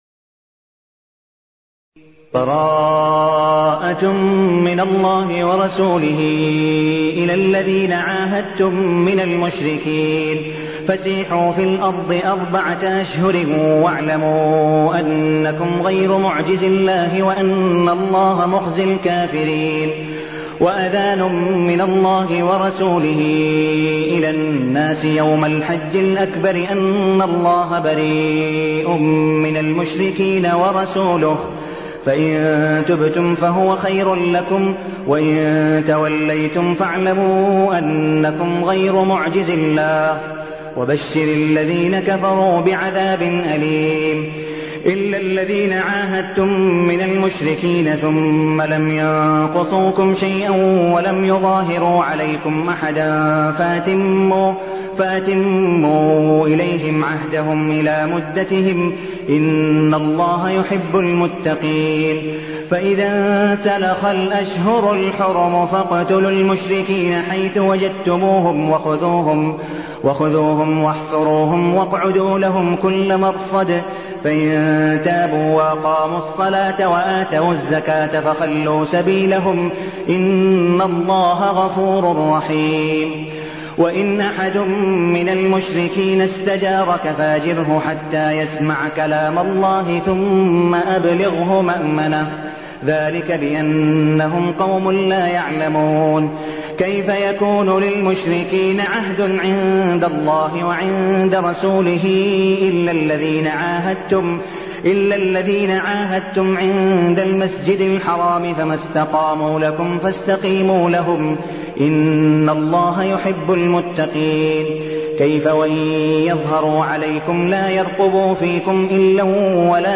المكان: المسجد النبوي الشيخ: عبدالودود بن مقبول حنيف عبدالودود بن مقبول حنيف التوبة